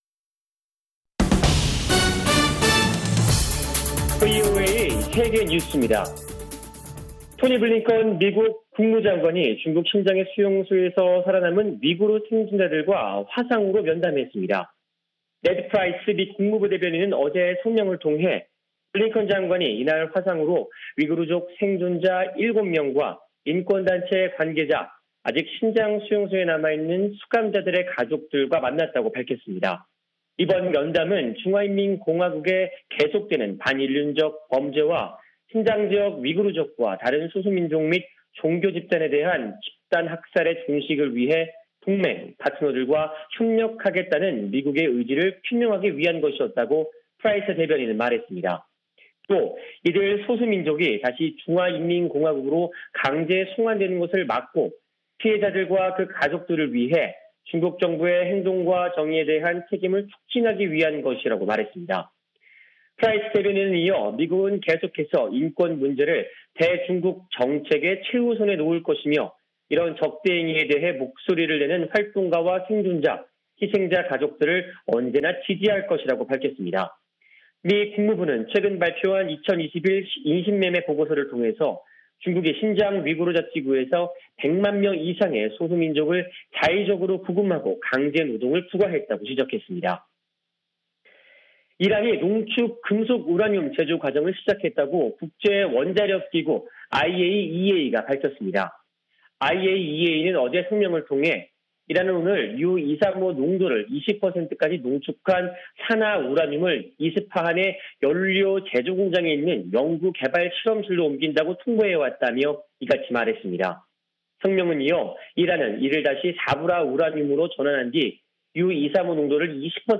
VOA 한국어 간판 뉴스 프로그램 '뉴스 투데이', 2021년 7월 7일 2부 방송입니다. 미 국무부 대북특별대표와 중국 외교부 한반도 사무 특별대표가 처음으로 전화통화를 했으나, 북한 문제에 대한 두 나라의 시각차가 여전하다고 전문가들이 말했습니다. 조 바이든 미국 정부가 제재정책에 대한 검토를 진행 중이라고 미국 언론이 보도했습니다. 조 바이든 미국 대통령과 시진핑 중국 국가주석이 머지않아 관여할 기회가 있을 것이라고 커트 캠벨 백악관 미 국가안보회의(NSC) 인도태평양 조정관이 밝혔습니다.